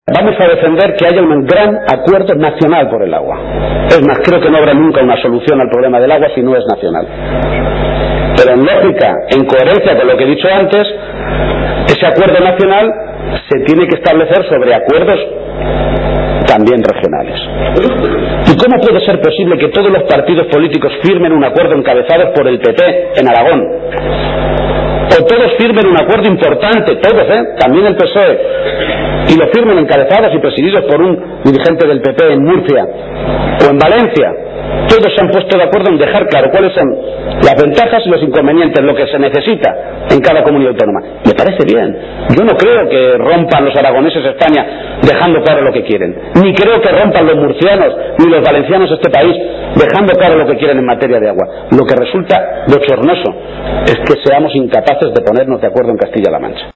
Audio Page-clausura Congreso PSOE C-LM-6